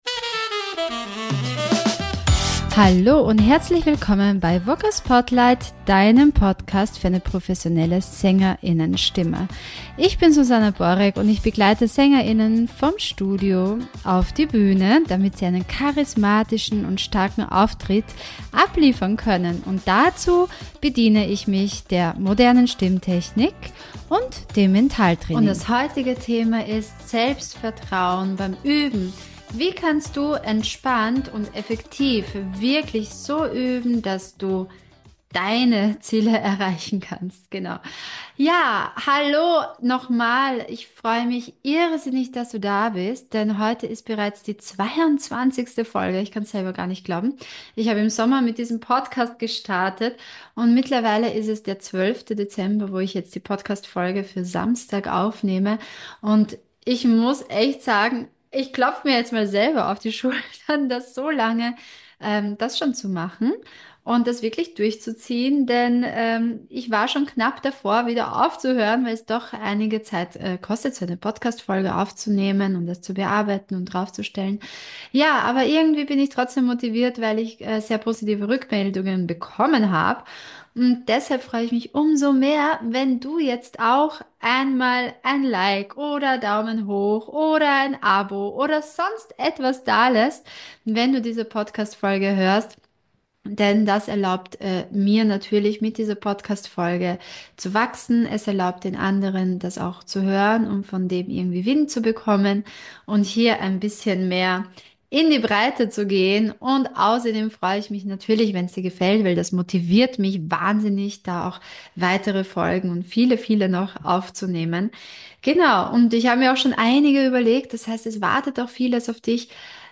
Das Mikro, welches ich nutze ist dieses: Blue Microphones Yeti